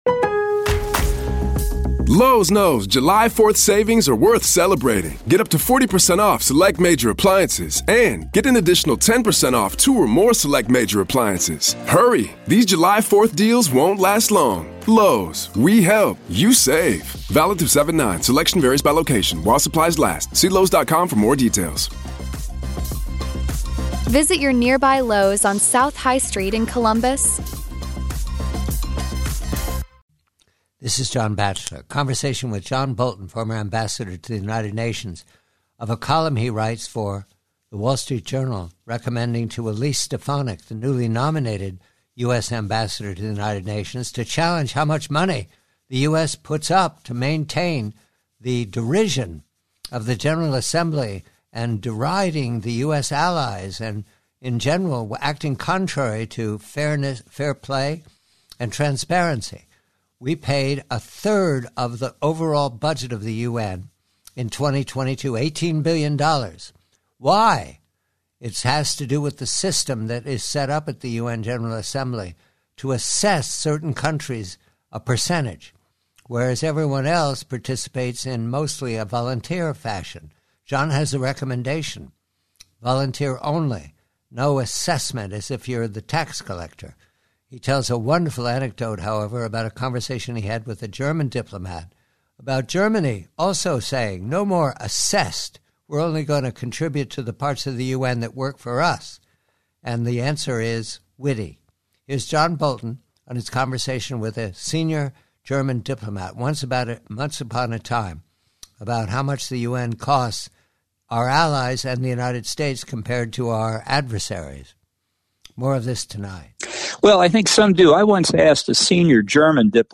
PREVIEW: UN: US: Conversation with former UN Ambassador John Bolton regarding the outsized "assessed contributions" that the US pays for the General Assembly -- and how the German delegation regards the assessment it is directed to pay.